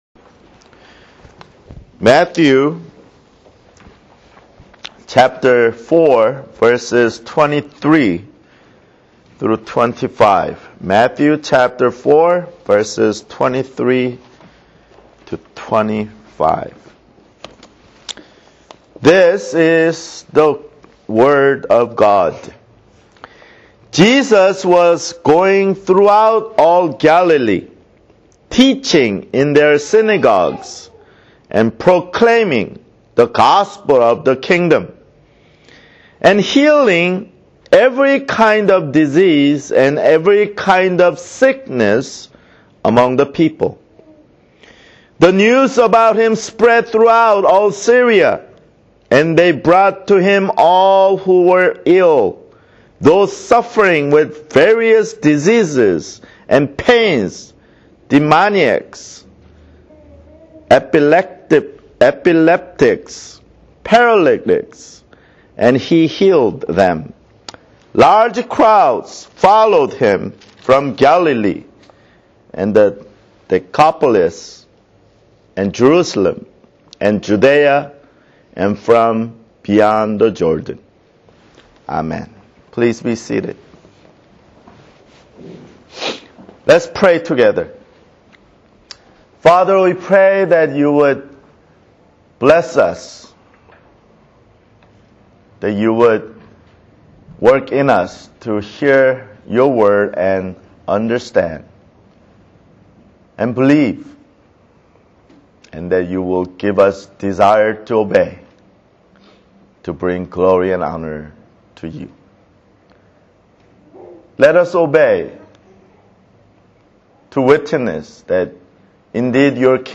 [Sermon] Matthew (12)